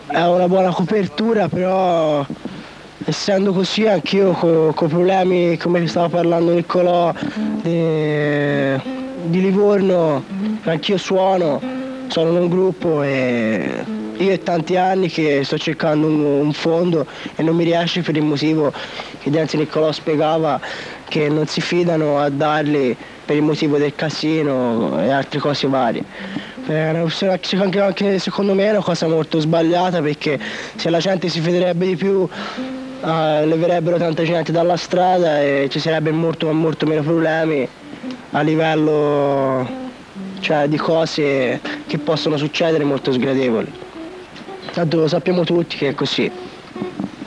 MusicaDroga Intervista.
monologo uno